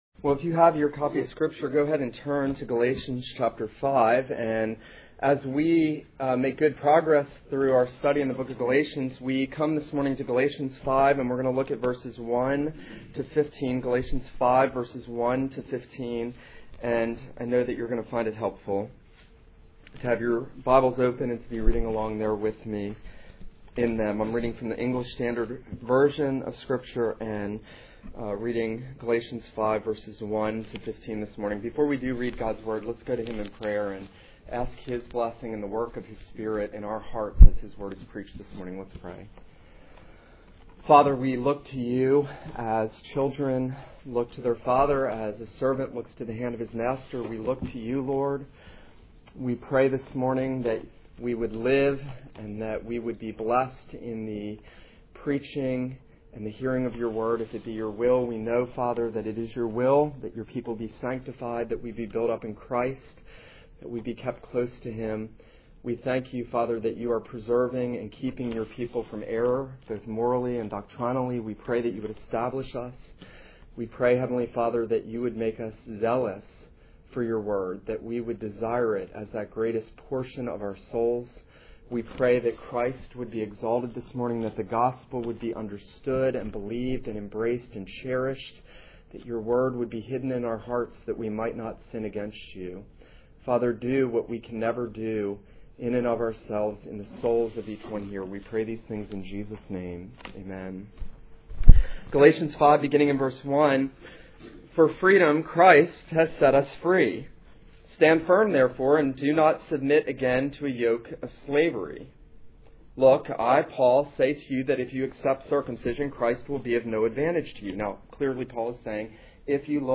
This is a sermon on Galatians 5:1-15.